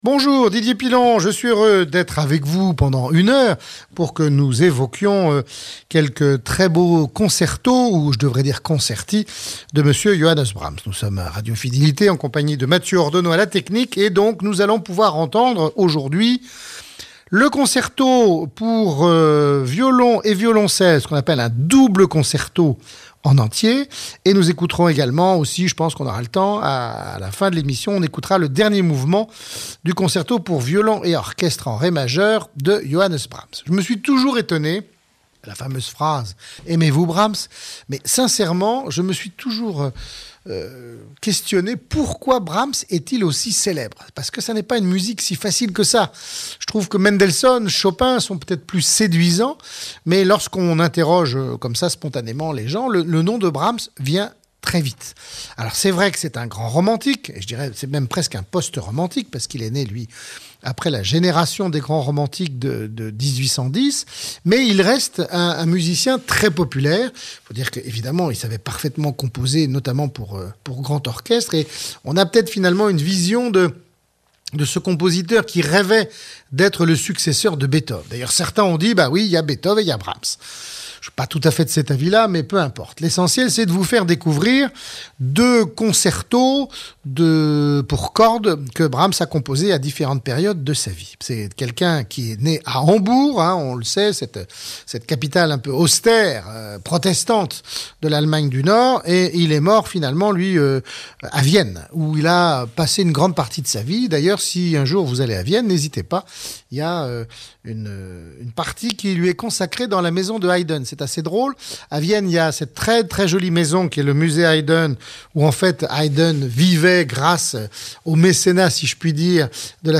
DP - Brahms - Concerto pour violon